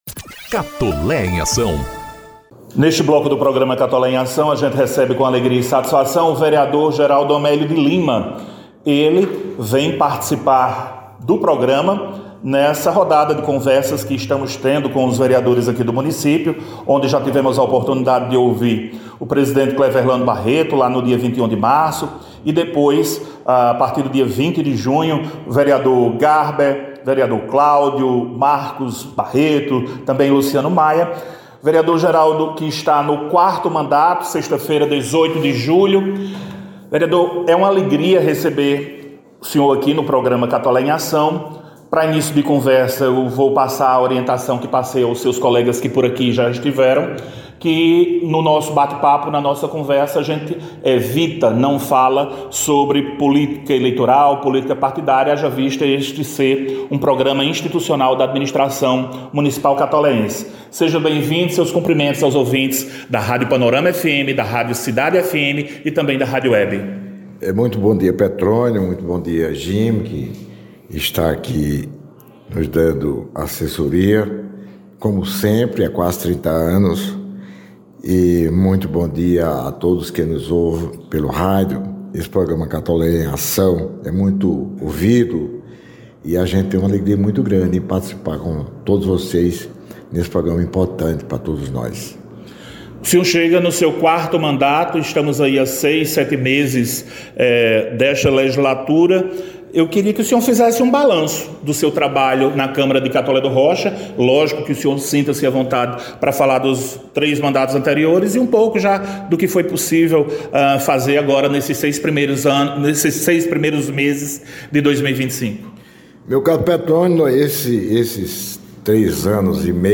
Vereador Geraldo Amélio faz balanço de mandato e destaca avanços e desafios de Catolé do Rocha.
Na edição do programa Catolé em Ação desta sexta-feira, 18 de julho, o convidado foi o vereador Geraldo Amélio de Lima, que está em seu quarto mandato na Câmara Municipal de Catolé do Rocha.